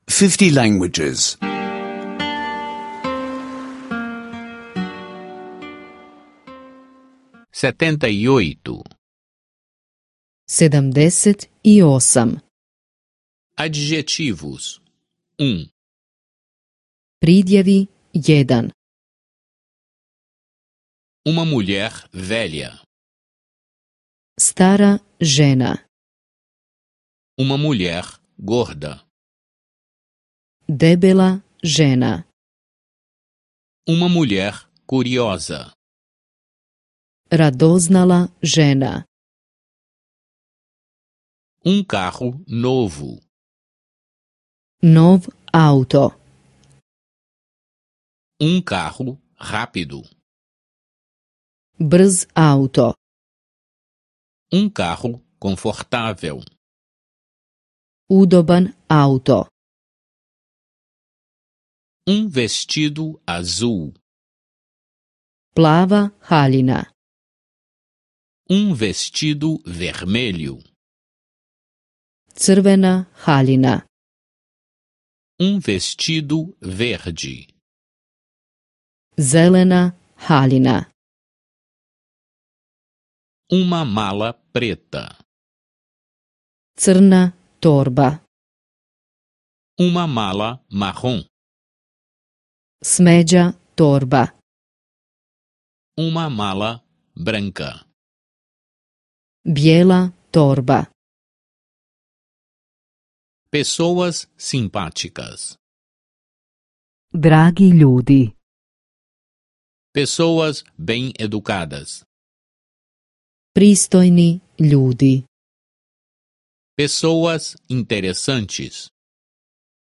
Aulas de croata em áudio — escute online